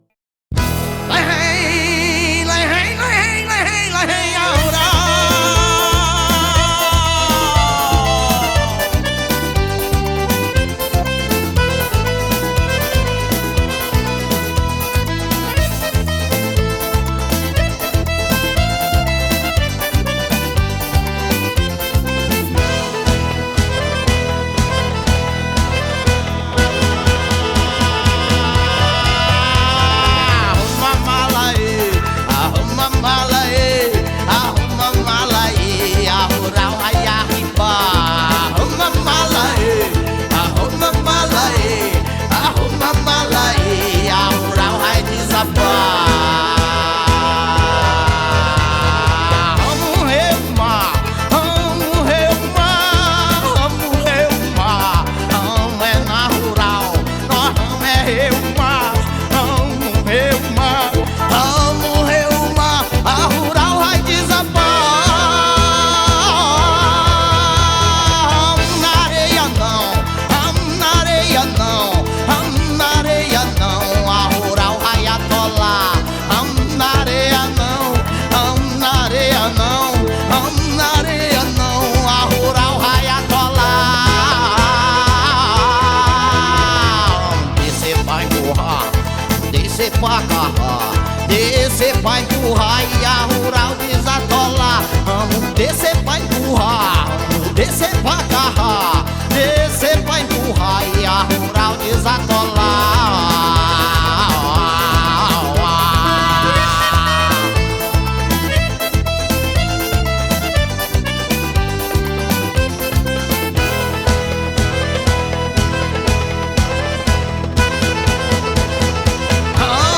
2025-01-05 23:58:11 Gênero: Forró Views